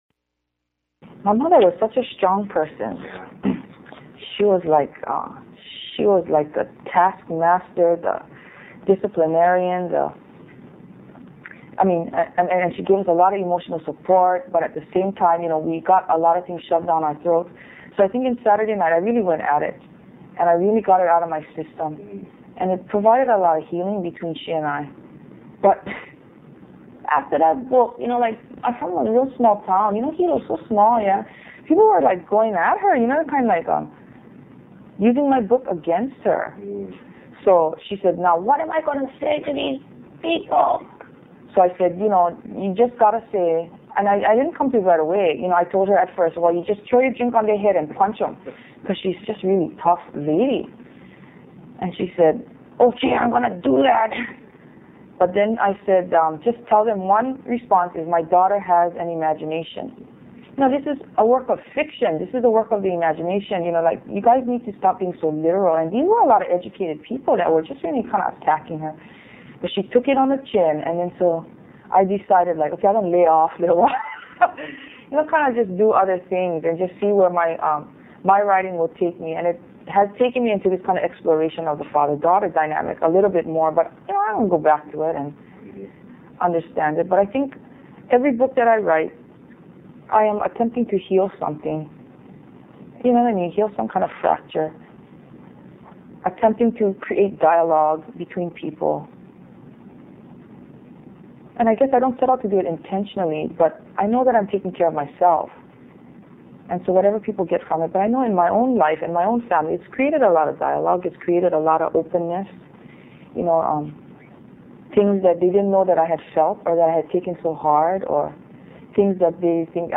Q&A with Lois-Ann Yamanaka